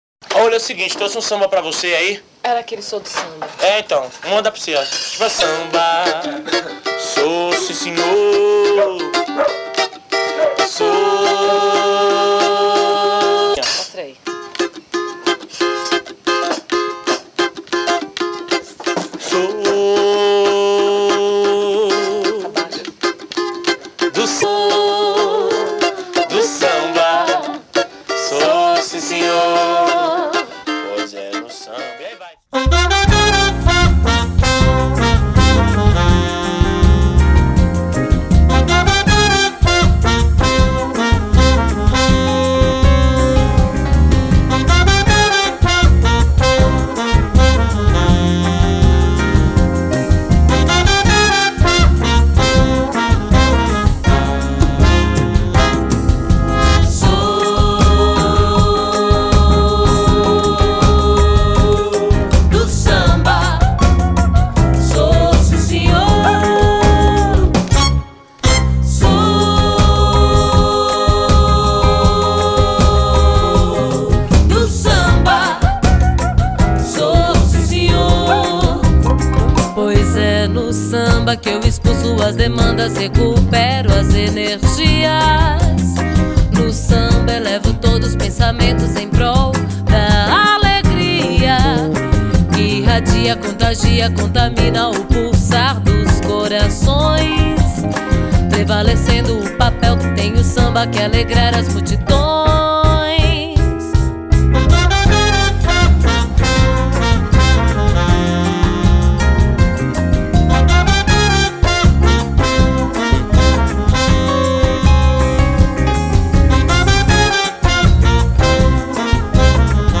Nos teclados
trompete
trombone
saxofone